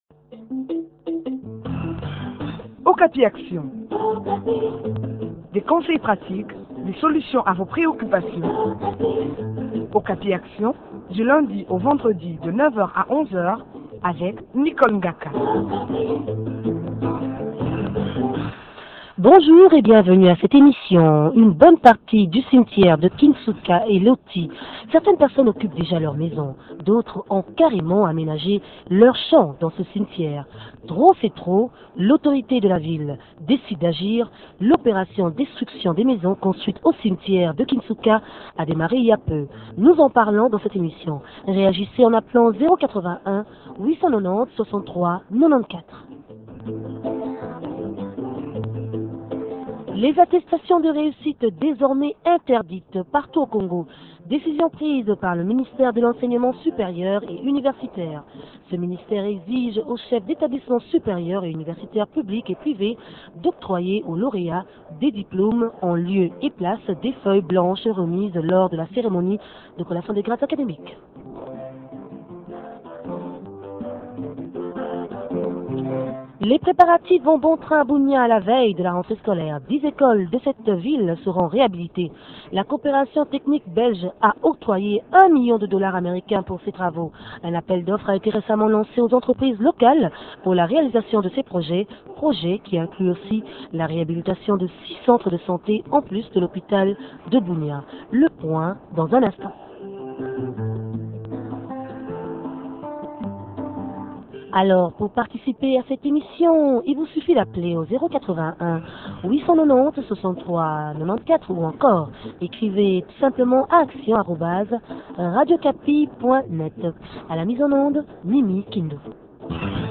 M.Batumona, vice gouverneur de la ville de Kinshasa